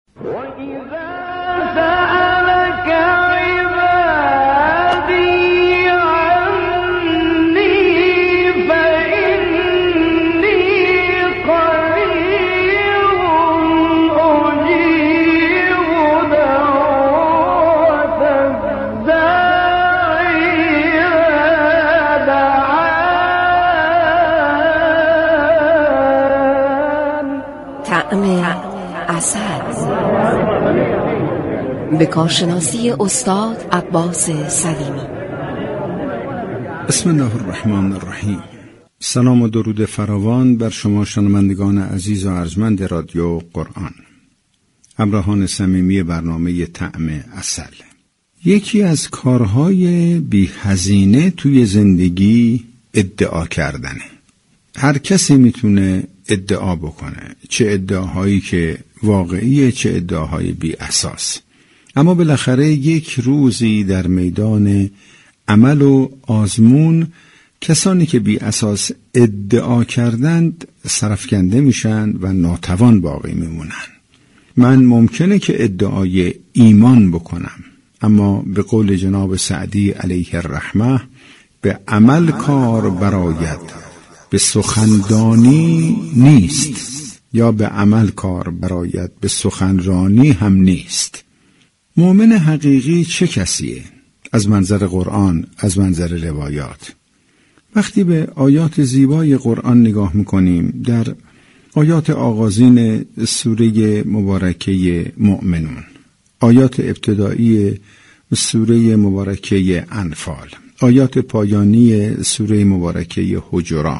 در بخش طعم عسل برنامه صبحگاهی تسنیم رادیو قرآن